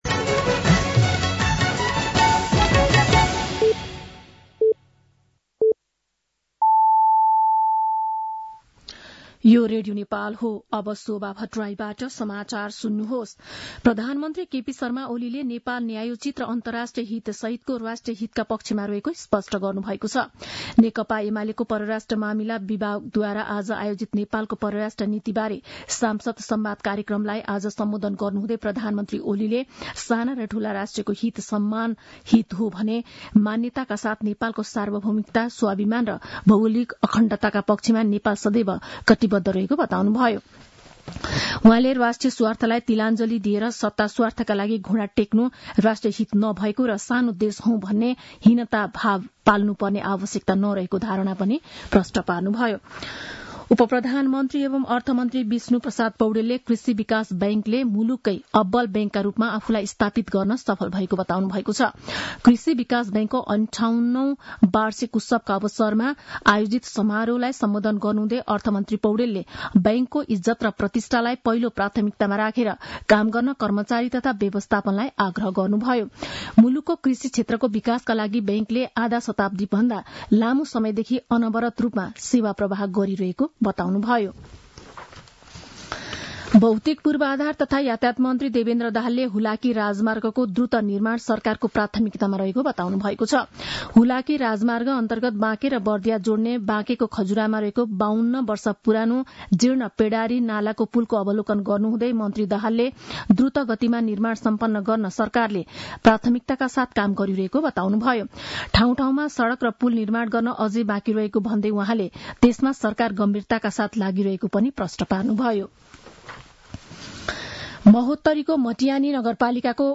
दिउँसो ४ बजेको नेपाली समाचार : ८ माघ , २०८१